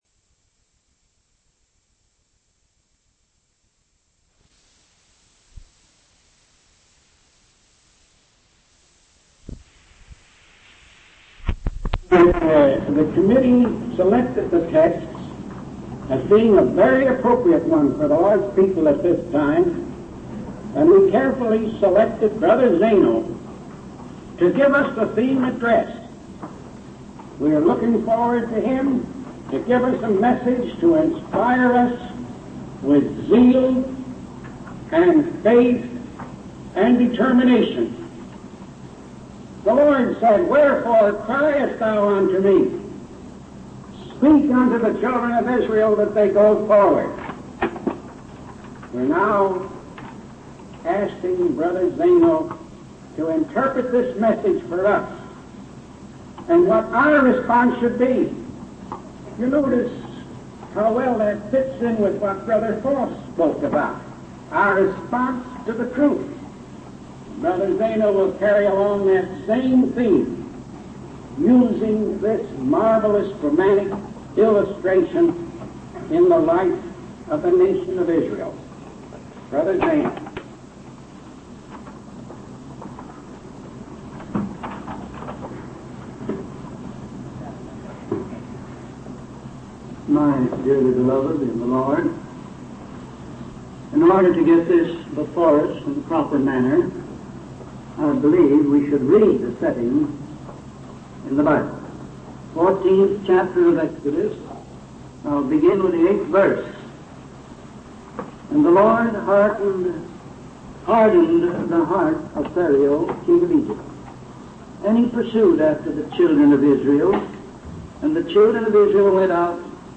From Type: "Discourse"
Given in Bloomington, IN 1953